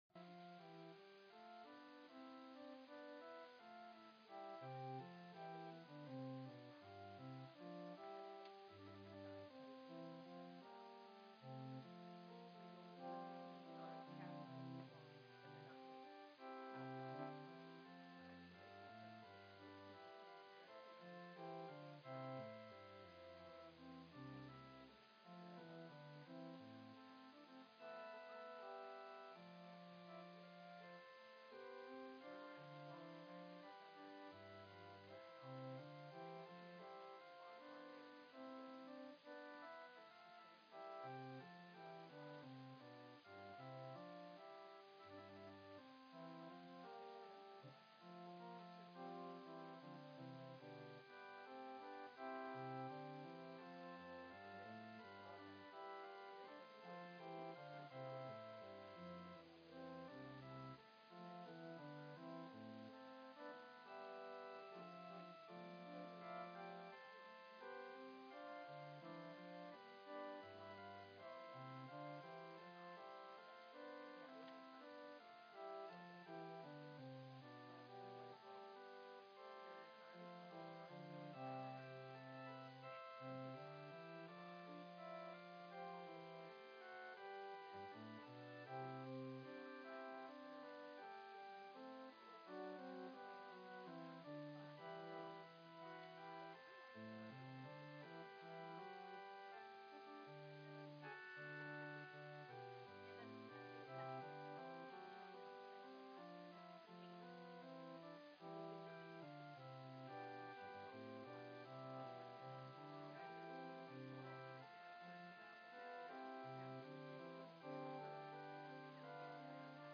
Passage: Mark 11:1-11 Service Type: Special « Sunday March 17